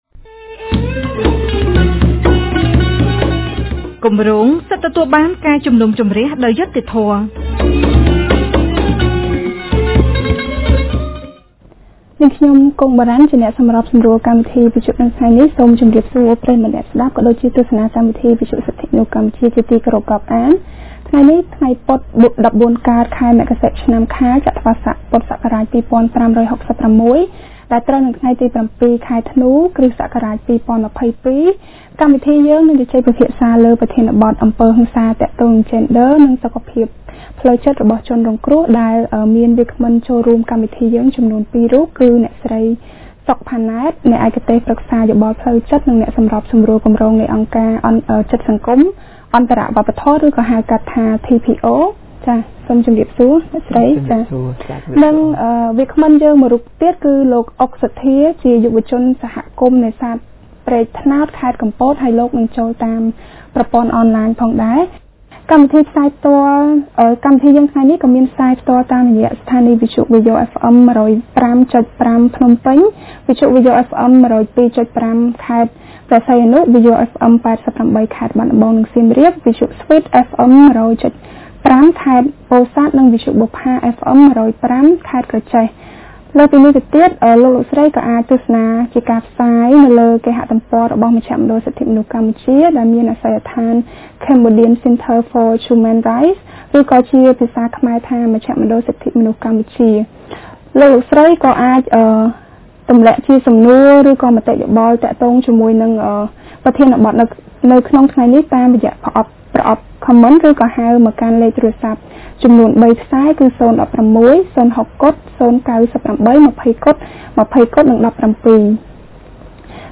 On Wednesday 07 December 2022 CCHR’s Fair Trial Rights Project (FTRP) held a radio program with a topic on Gender-Based Violence and Victim’s Mental Health.